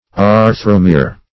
\Ar"thro*mere\